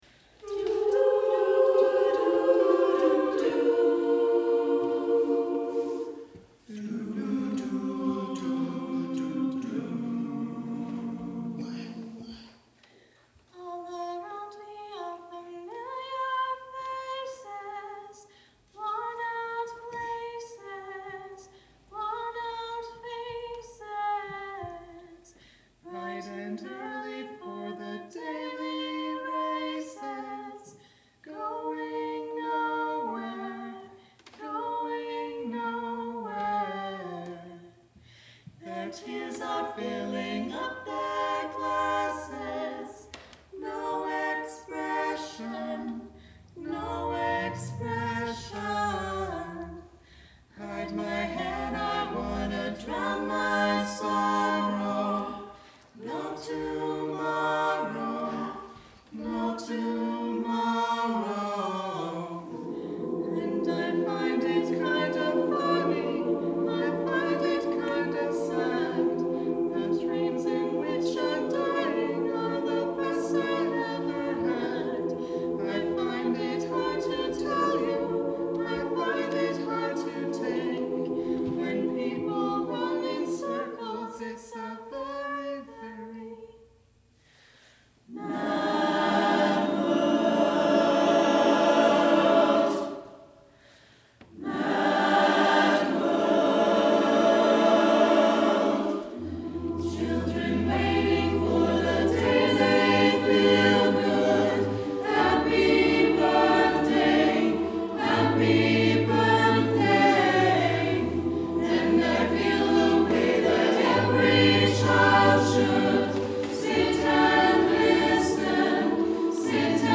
Below you will find select audio and video from our past performances for your listening and viewing enjoyment.
From our January 30, 2016 concert, Journeys: